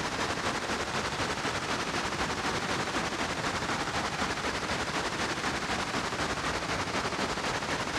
STK_MovingNoiseB-120_01.wav